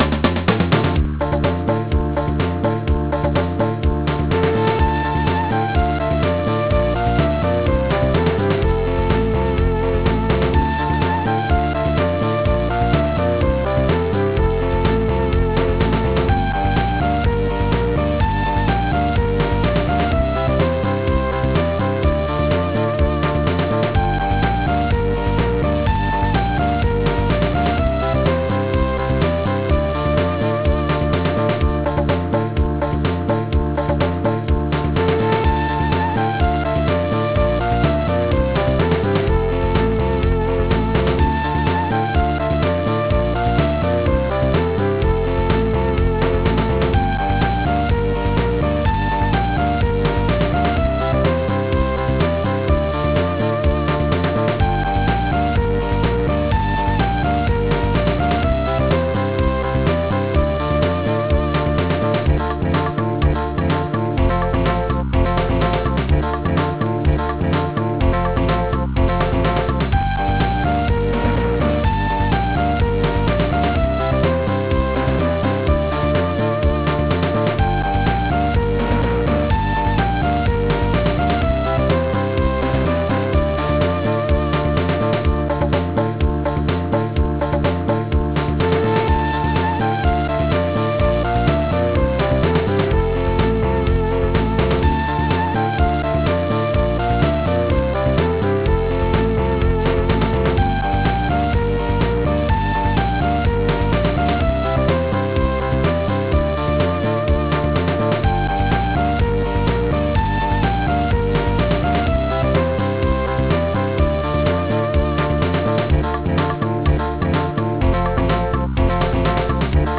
Latin music(Viva la Lambada); there's rarely a sad song in the house - how can there be